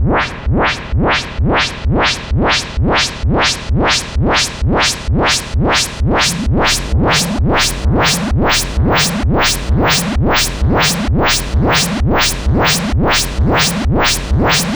FX 130-BPM.wav